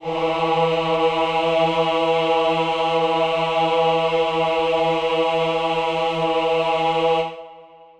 Choir Piano (Wav)
E3.wav